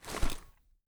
gear_rattle_weap_medium_10.ogg